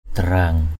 /d̪a-raŋ/ 1.